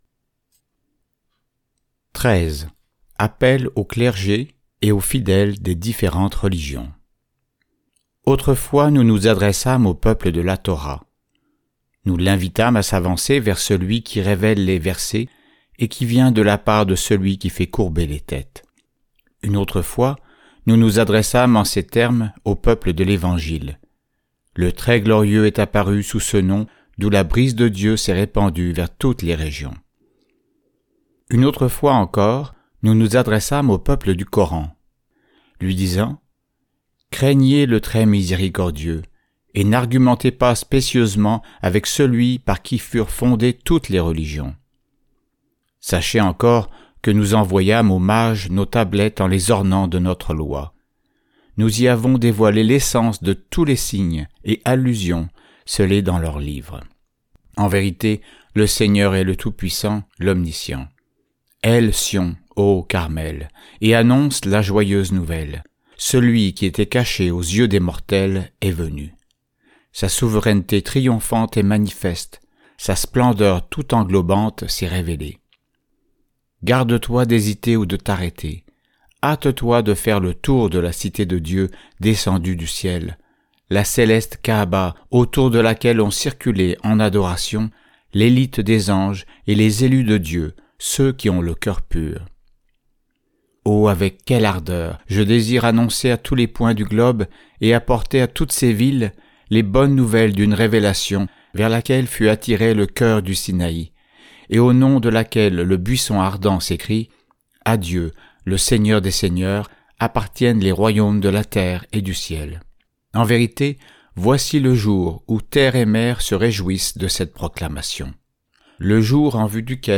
Enregistrements audio en français des écrits sacrés baha'is